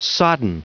Prononciation du mot sodden en anglais (fichier audio)
Prononciation du mot : sodden